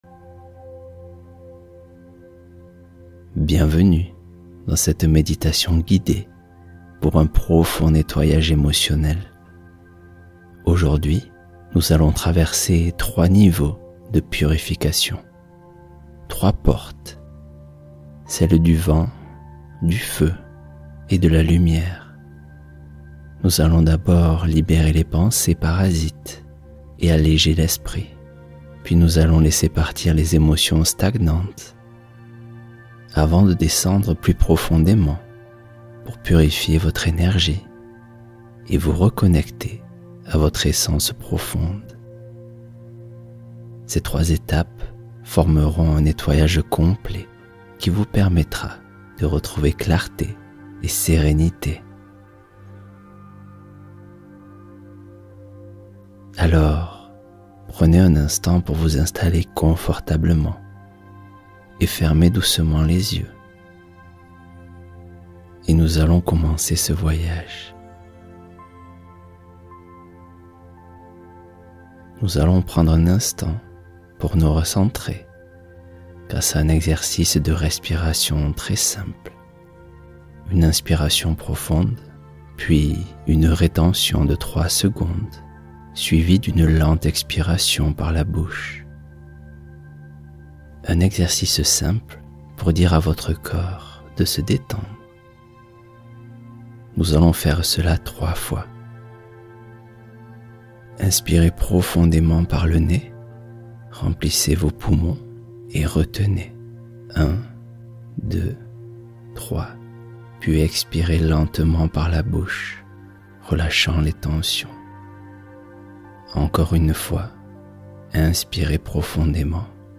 Confiance retrouvée : méditation guidée pour des changements durables